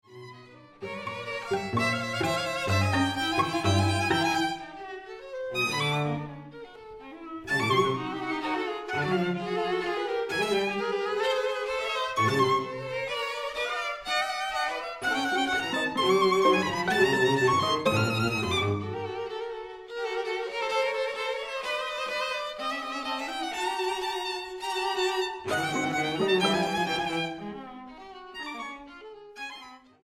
Energico 3.40